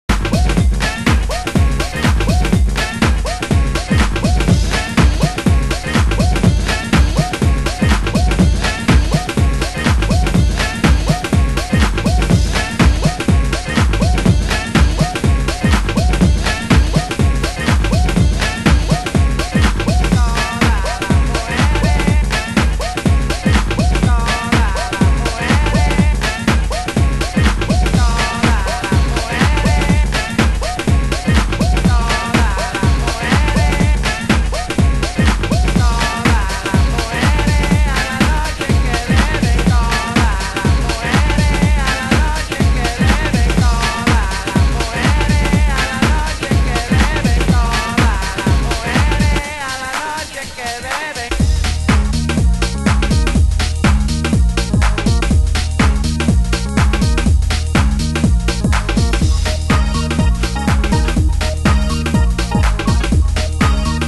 盤質：軽いスレ傷、少しチリパチノイズ有/ラベルにシール跡有